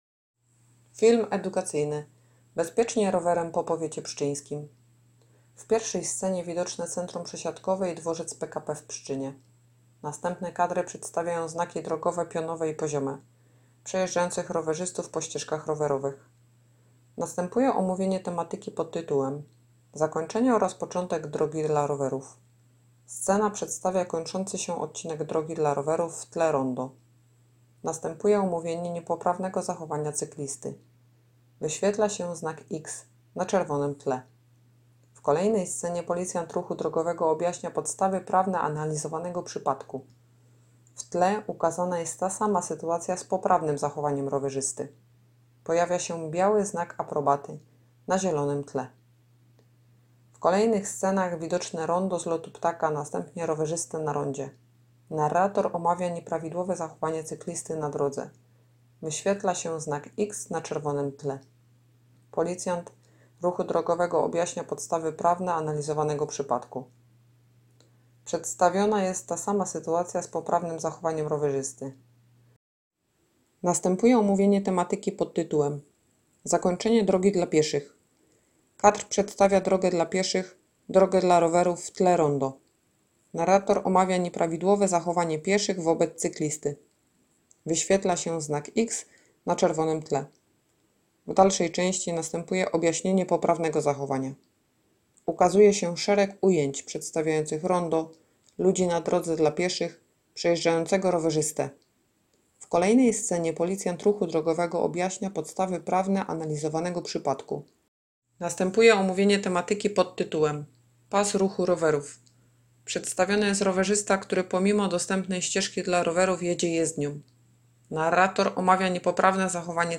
Opis nagrania: Audiodeskrypcja do filmu Bezpiecznie rowerem po powiecie pszczyńskim